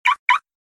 เสียงเรียกเข้าปลดล็อกรถยนต์
เสียงแจ้งเตือนข้อความ เสียงเรียกเข้า Car Lock
คำอธิบาย: นี่คือ เสียงเรียกเข้าปลดล็อกรถยนต์ เสียงนี้จะถูกปล่อยออกมาเมื่อเรากดสมาร์ทคีย์ คุณสามารถดาวน์โหลดโทรศัพท์เพื่อใช้เป็นเสียงเรียกเข้าโทรศัพท์โดยใช้ลิงก์ดาวน์โหลดด้านล่าง
Nhac-chuong-mo-khoa-o-to-www_tiengdong_com.mp3